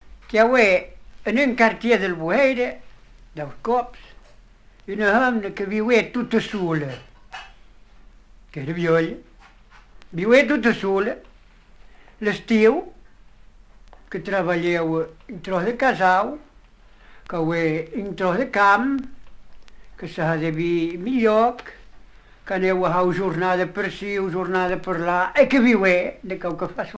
Catégorie Récit